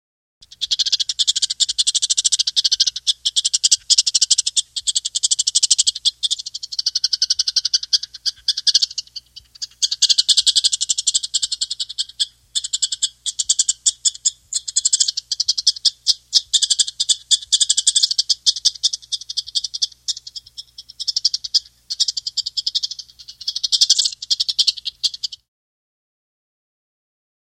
На этой странице собраны разнообразные звуки бурундуков — от веселого стрекотания до любопытного писка.
Звуки бурундука: чихание маленького зверька